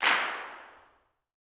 CLAPS 03.wav